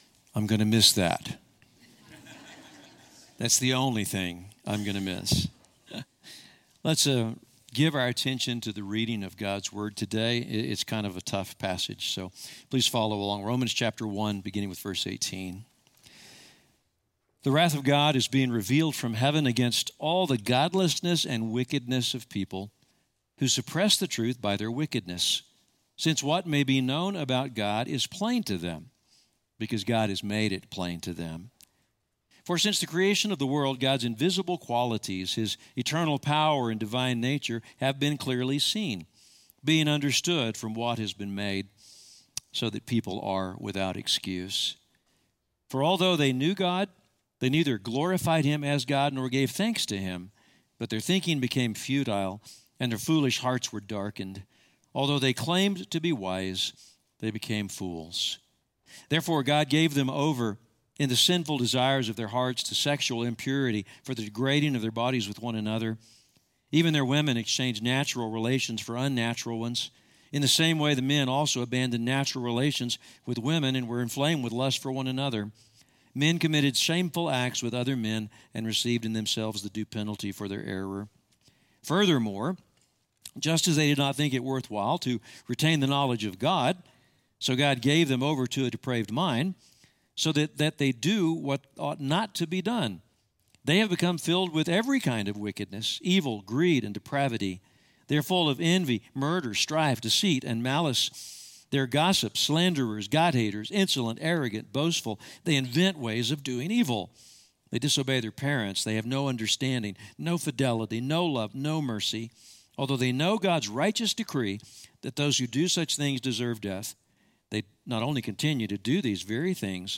In today’s final message of the Broken Saviors series, we walk through what might well be the most disturbing and ugly chapters in the entire Bible.